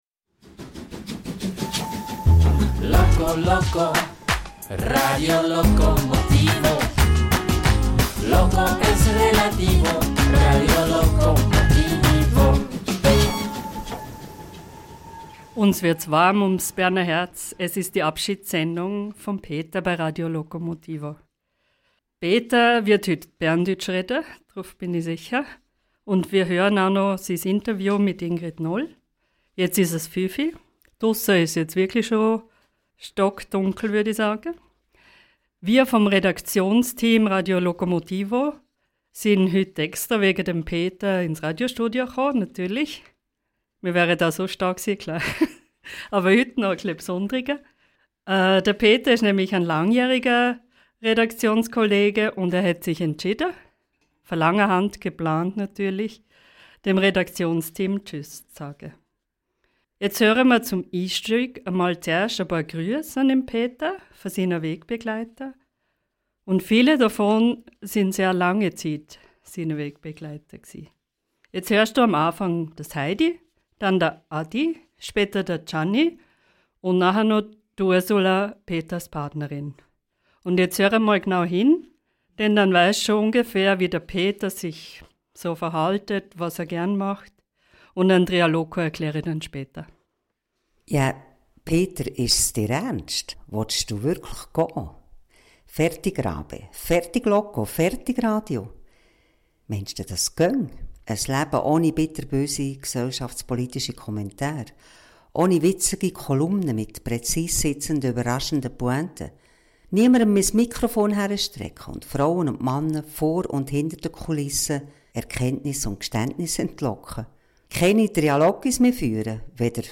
Diese Sendung ist ihm gewidmet. Wir schauen zurück, mit einem Gespräch, dass er im Jahr 2013 mit der Schriftstellerin Ingrid Noll geführt hat, wir hören eine letzte seiner schönen Kolumnen und befragen ihn nach seinen Zukunftsplänen.